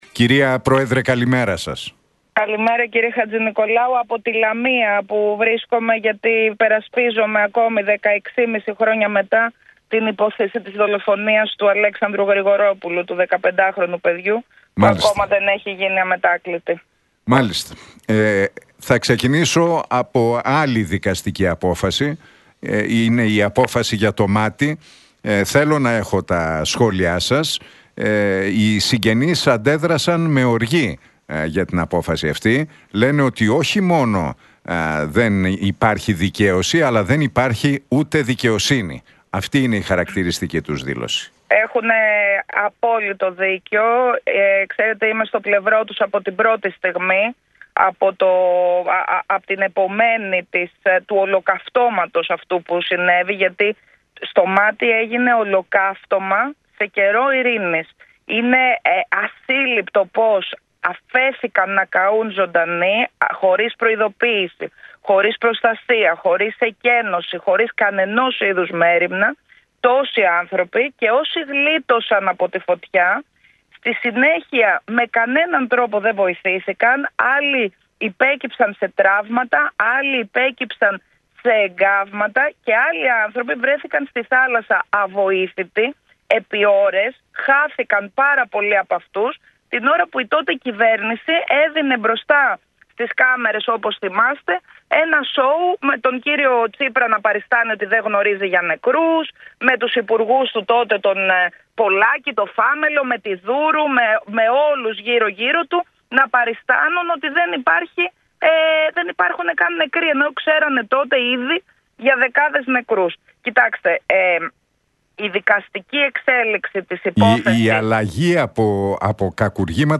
Κωνσταντοπούλου στον Realfm για Μάτι: Δεν υπάρχει δικαίωση, χωρίς κακουργηματική καταδίκη - Η τότε κυβέρνηση έδινε στις κάμερες ένα σόου, με τον κ. Τσίπρα να παριστάνει ότι δεν γνωρίζει για νεκρούς